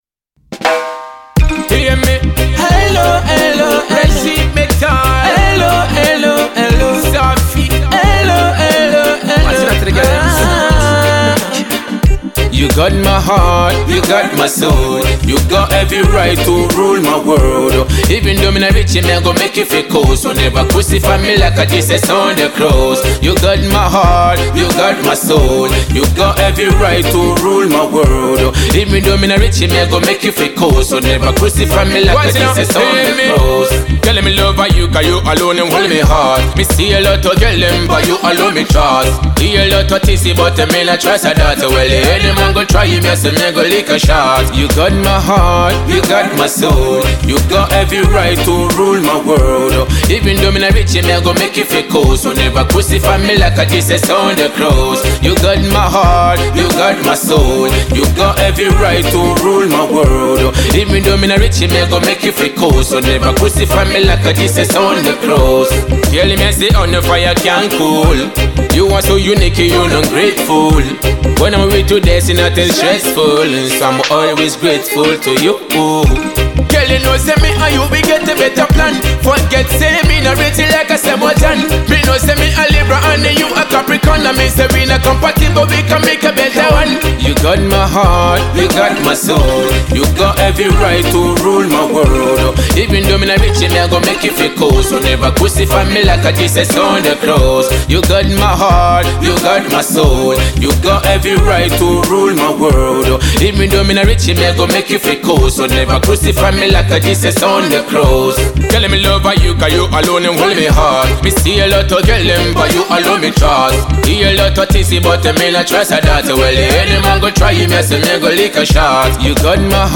a dancehall artist